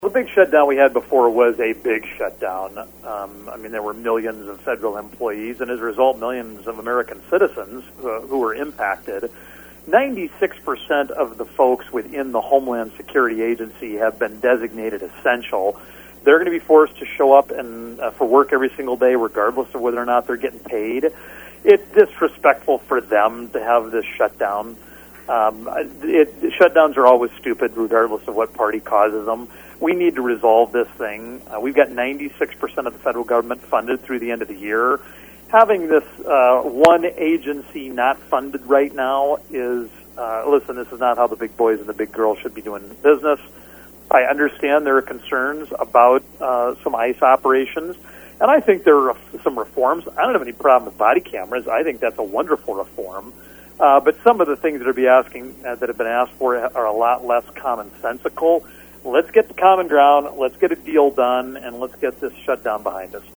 In a swing through Huron on Monday representation Dusty Johnson compared this shutdown with the larger one last fall.